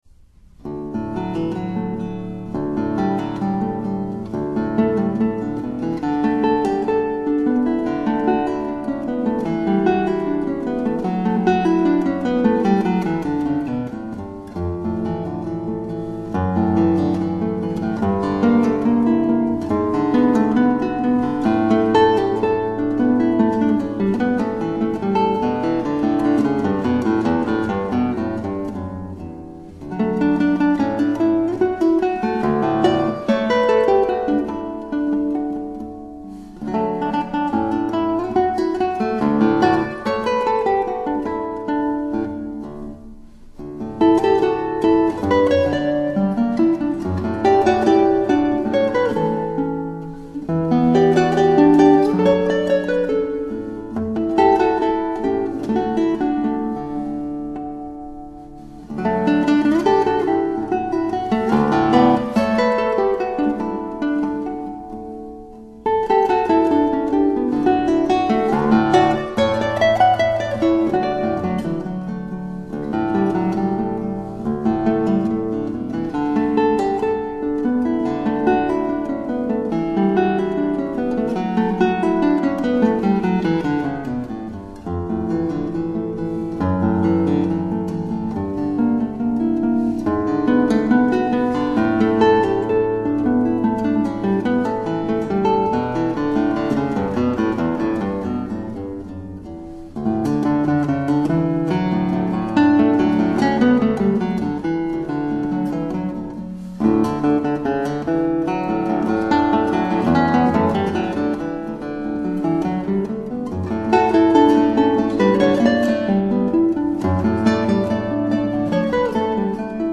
[4/7/2010]古典吉他演奏·新疆民歌（阿拉木汗）